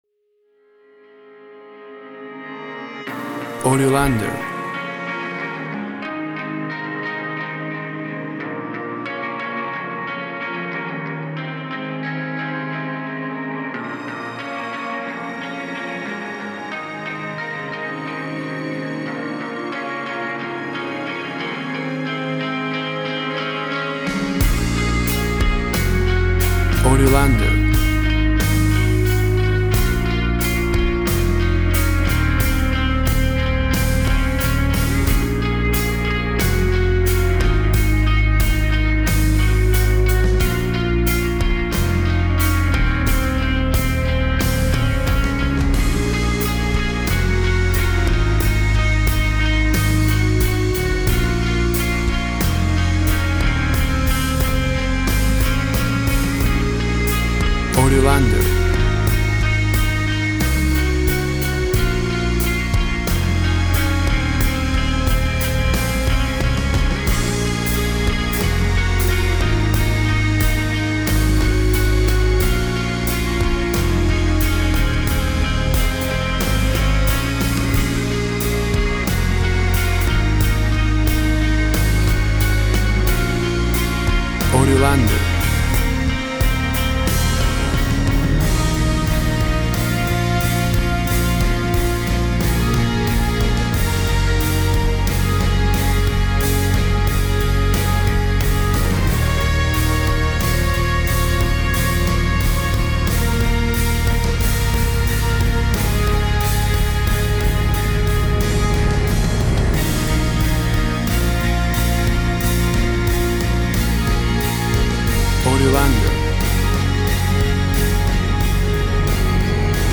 Post Rock/Orchestral track
Tempo (BPM) 90